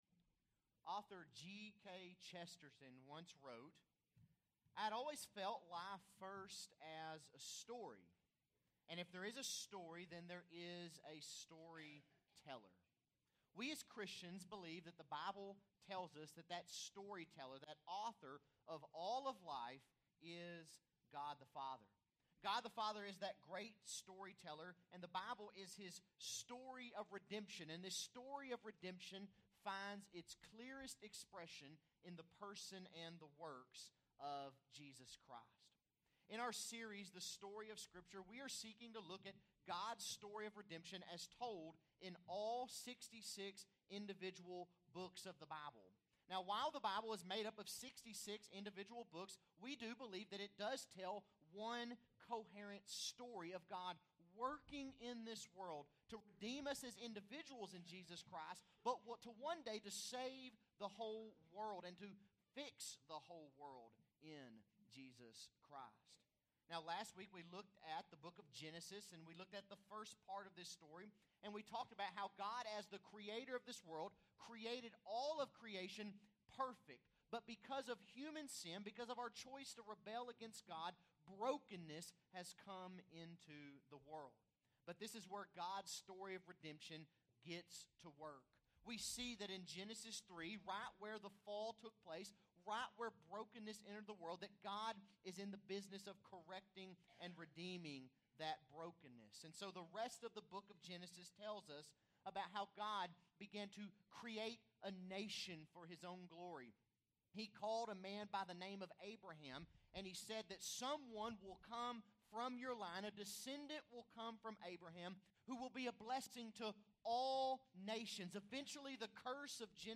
Bible Text: Exodus | Preacher